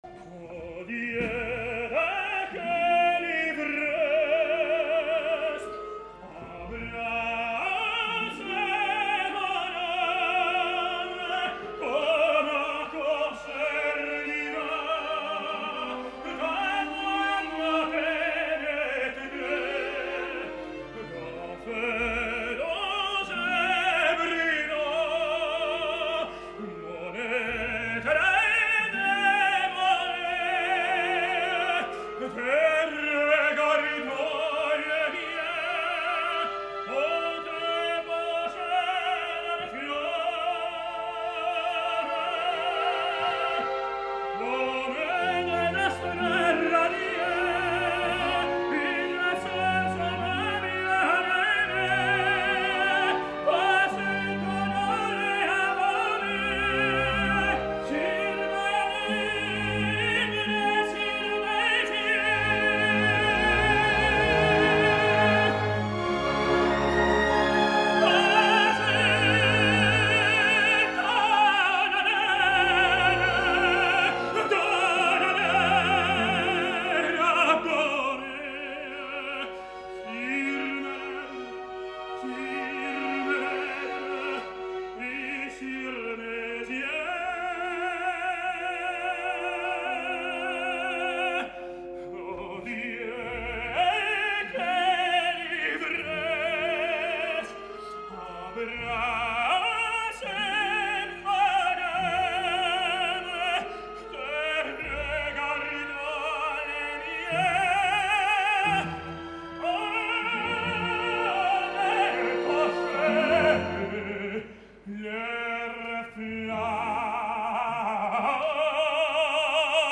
Més tard i abans de cantar l’exigent duo amb Giulietta, Hoffmann té un dels moments més bells de tota la partitura “Ô Dieu, de quelle ivresse” , que deixeu-me dir que Villazón va massacrar sense pietat i és que no podia amb la seva ànima.